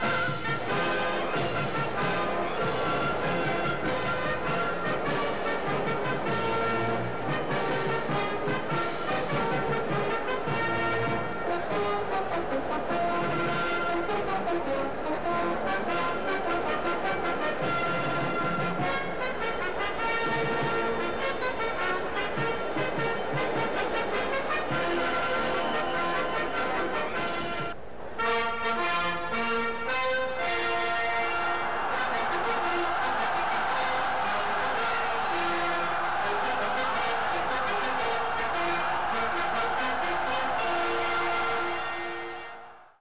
registrato dai nastri del film - effetti sonori